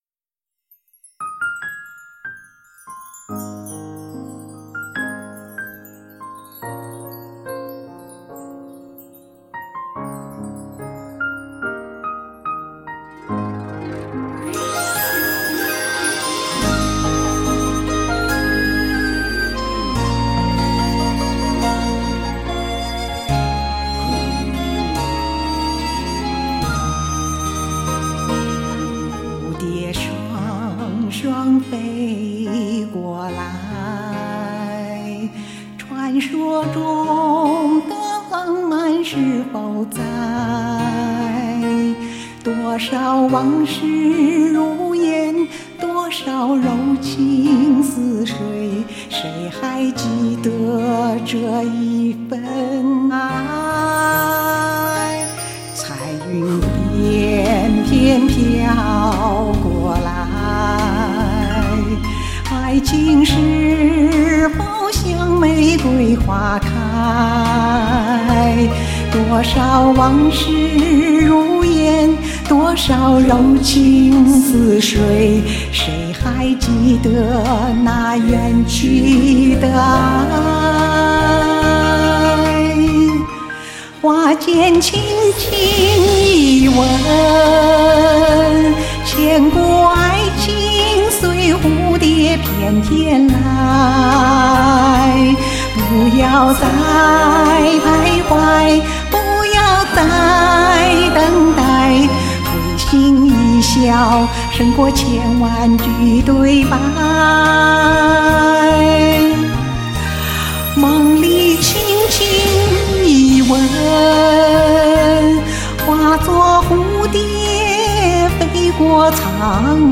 这也是我第一次在录音棚录制歌曲，比在家里录歌是要舒服多了。